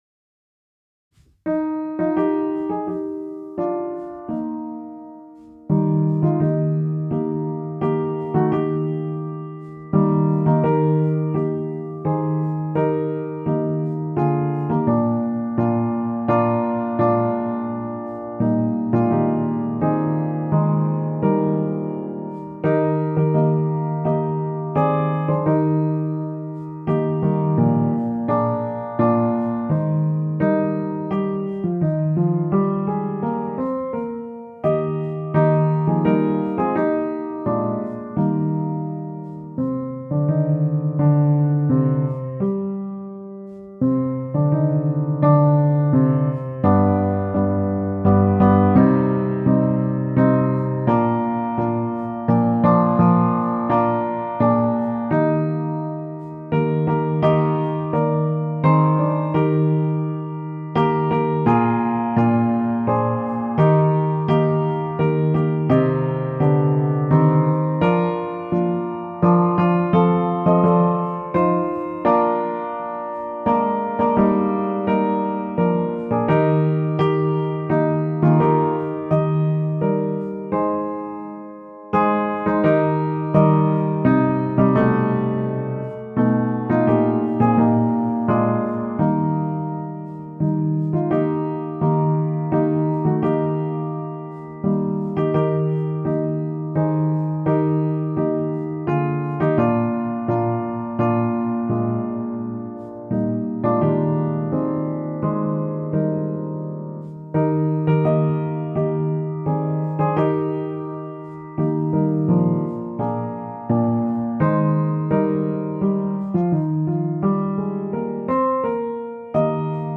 Tempo: Normaal